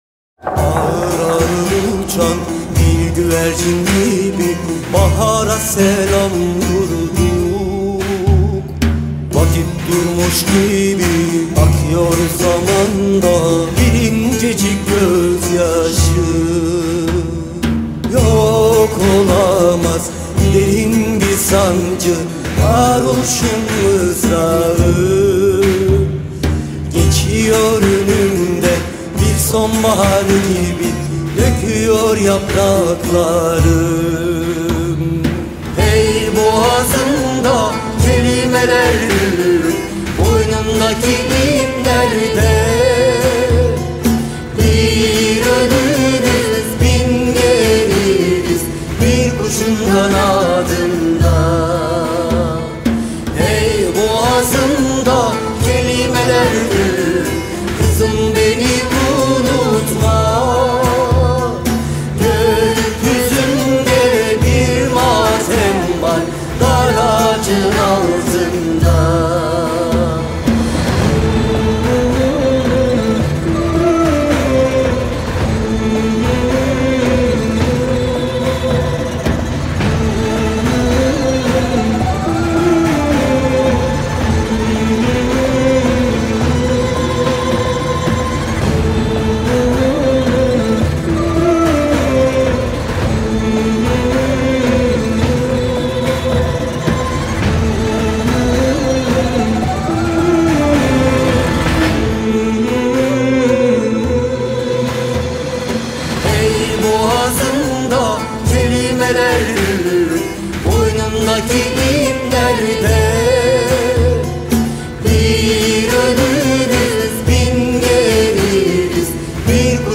duygusal hüzünlü üzgün şarkı.